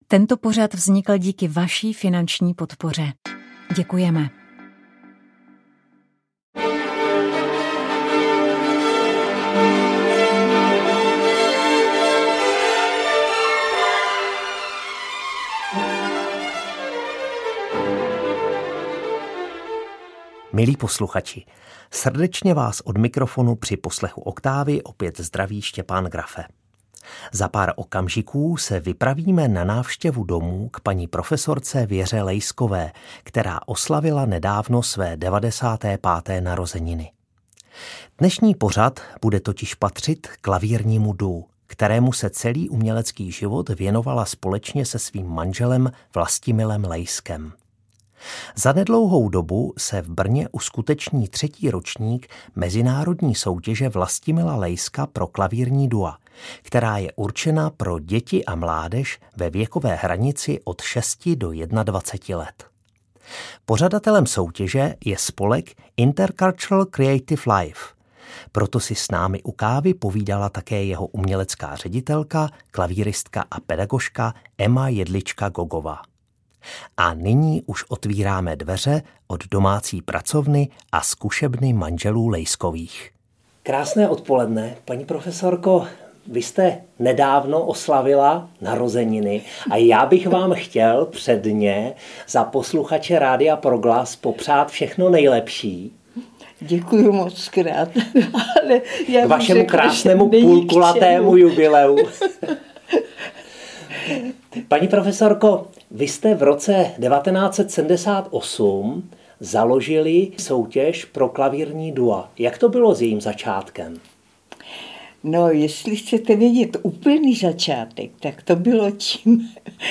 To je základní půdorys pro rozhovor, ve kterém vám představíme festival plný novinek. Letos uvede devět světových, jednu evropskou a pět českých premiér.